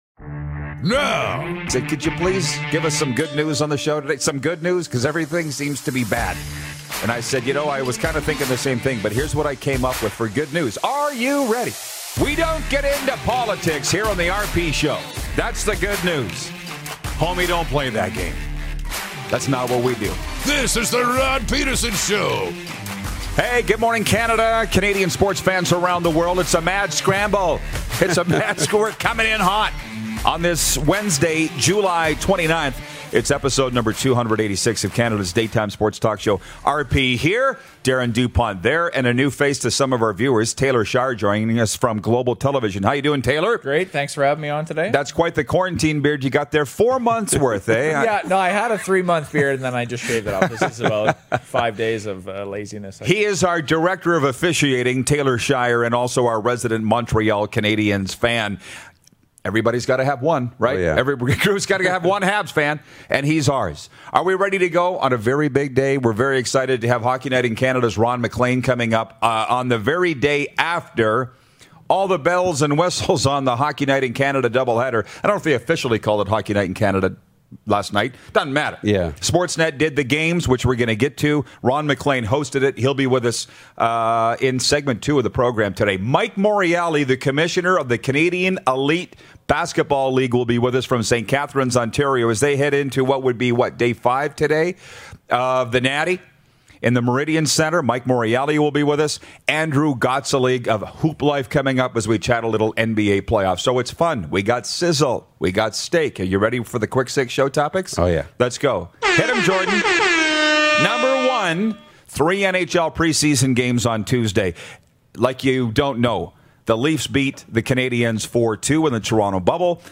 Big Day on the show with Hockey Night in Canada Host RON MACLEAN!
Rogers Hometown Hockey and Hockey Night in Canada Host Ron MacLean in with us in Segment 2!